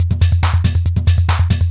DS 140-BPM A3.wav